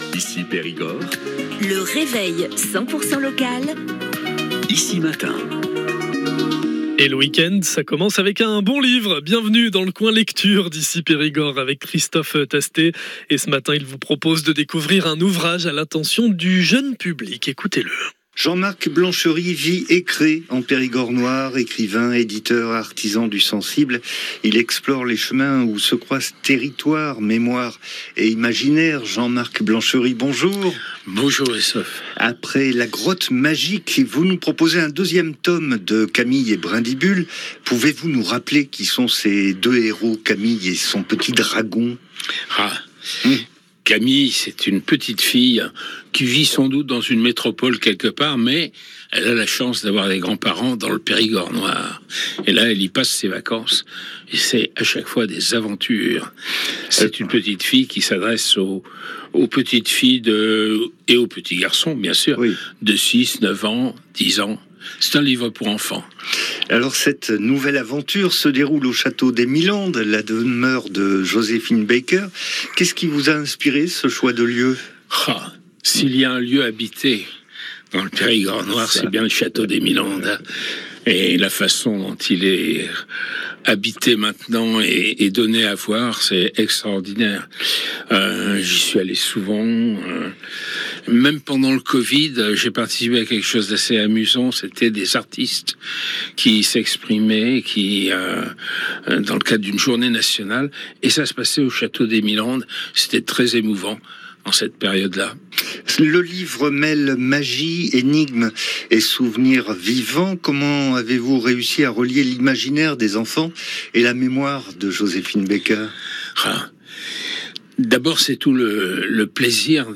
L’interview d’ICI Périgord